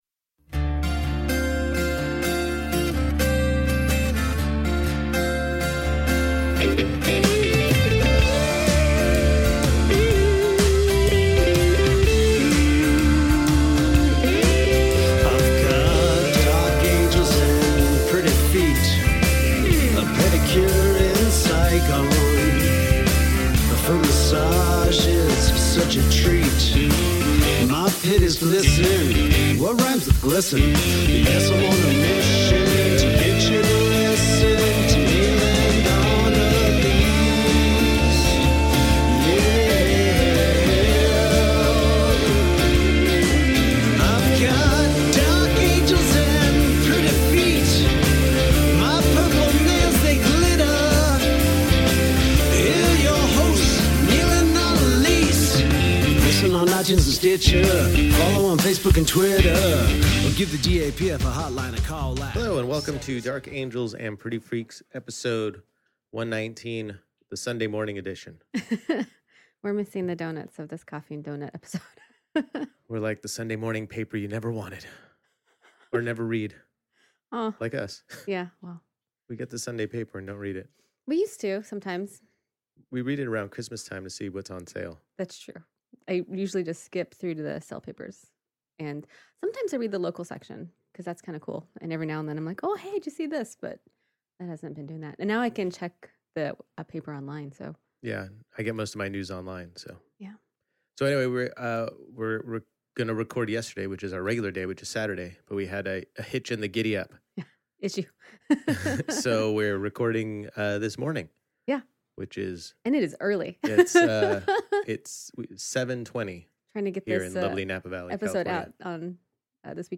a rare, sleepy Sunday Morning podcast. We chat Birthday thank you, Fathers Day Meal, Taking the Bus, NASCAR, Wrecked, a confusing Favorite 5 eccentric Household Items and so much more!